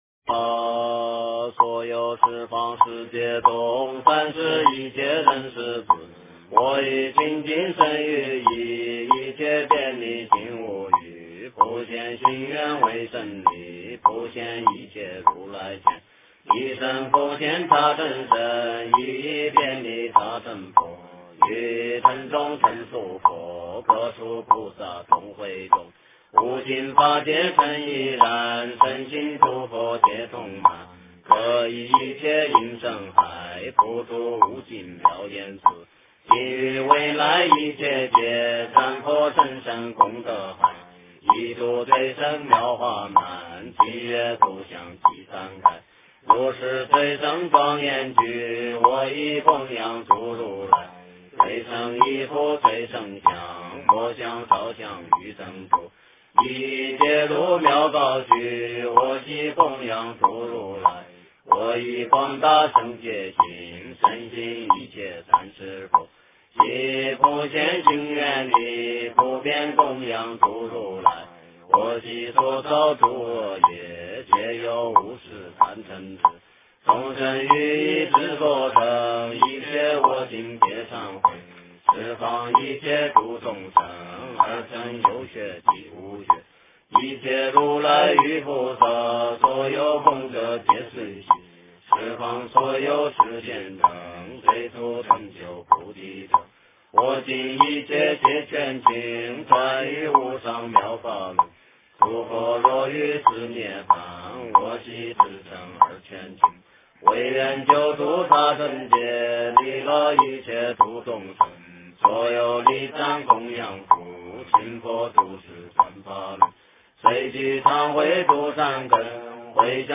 普贤行愿品偈--僧团 经忏 普贤行愿品偈--僧团 点我： 标签: 佛音 经忏 佛教音乐 返回列表 上一篇： 大方广佛华严经华严普贤行愿忏-上--僧团 下一篇： 阿弥陀经--悟因法师率众 相关文章 普佛(代晚课)(上)--僧团 普佛(代晚课)(上)--僧团...